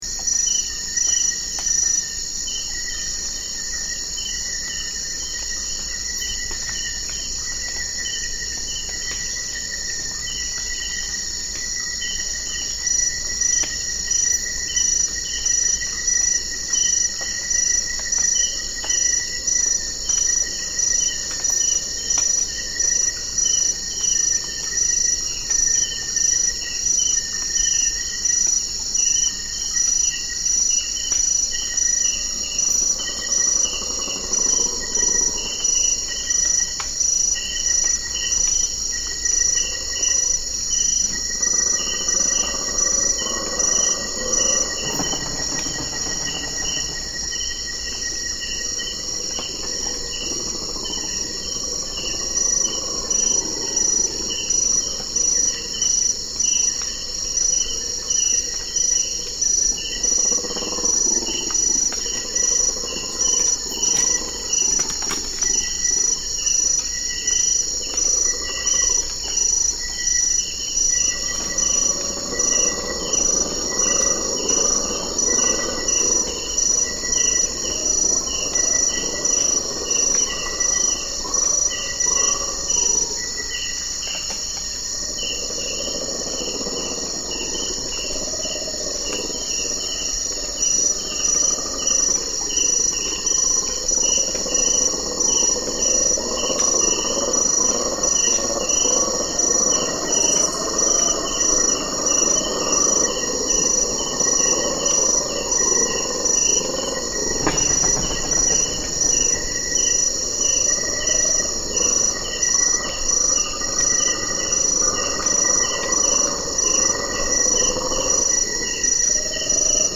Colobus chorus